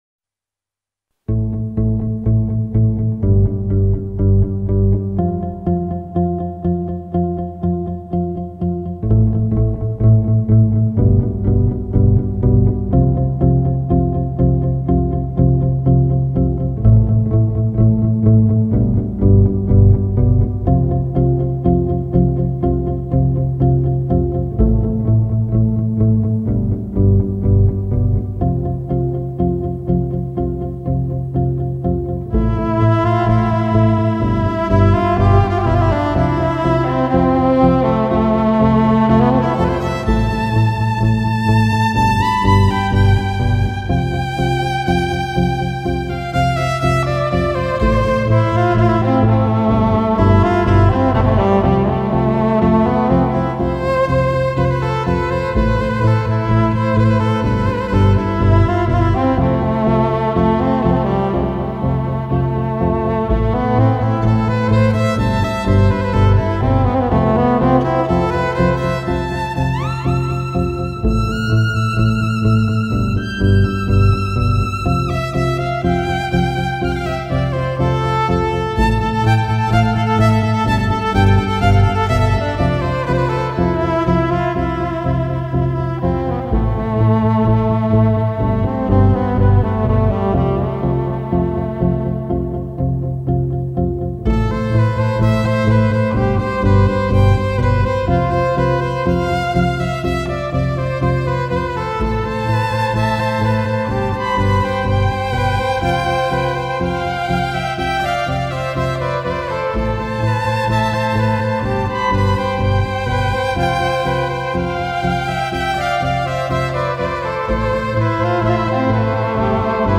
موسیقی بی کلام